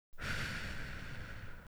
br10_Long_exhale.wav